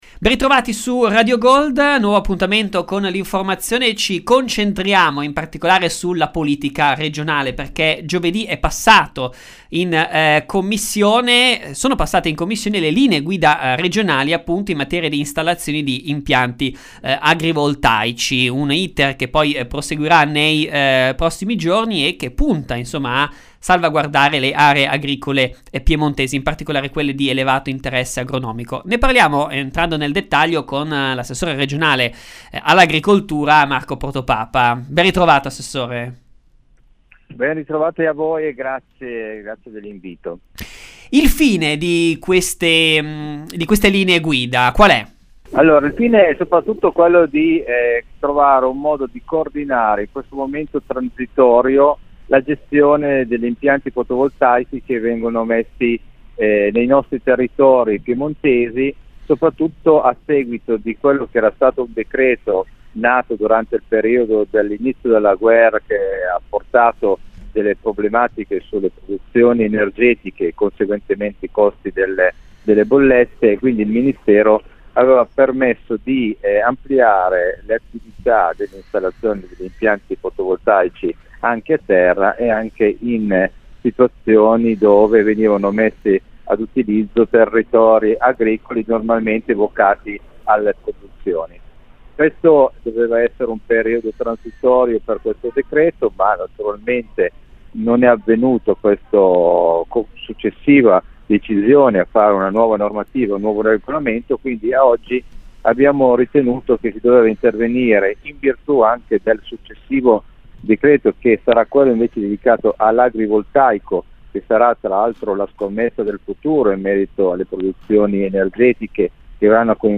PROVINCIA DI ALESSANDRIA – Su Radio Gold l’assessore regionale all’Agricoltura Marco Protopapa ha raccontato la delibera in materia di installazione di impianti agrivoltaici che in questi giorni sarà approvata, a tutela delle aree agricole.